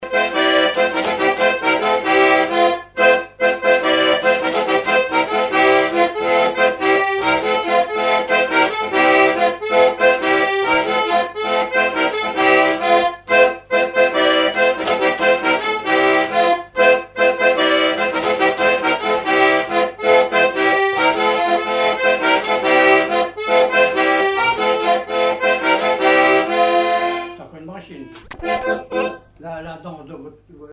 Base d'archives ethnographiques
Localisation Noirmoutier-en-l'Île (Plus d'informations sur Wikipedia)
Fonction d'après l'analyste danse : branle ; danse : branle : courante, maraîchine ;
Catégorie Pièce musicale inédite